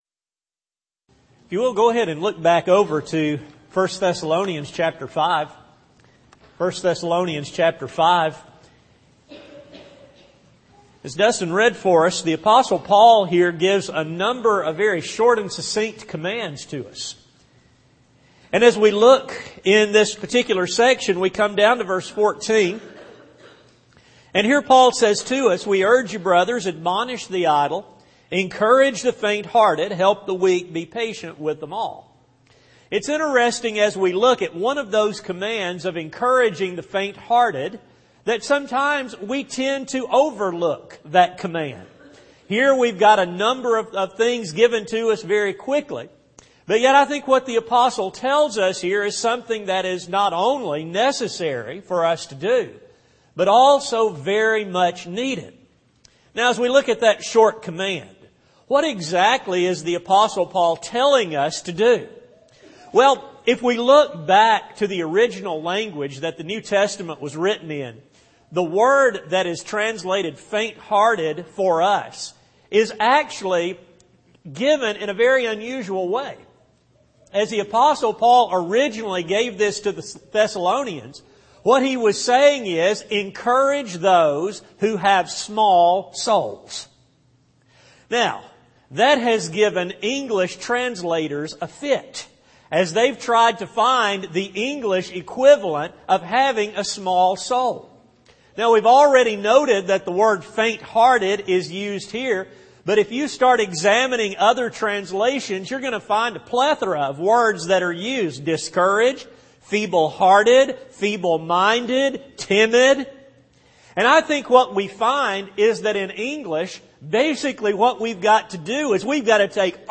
Service: Sun AM Type: Sermon